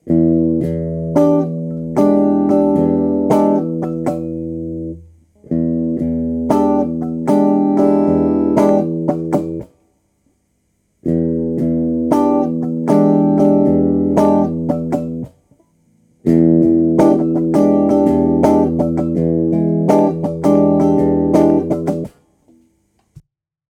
Guitare 1 : Rythmique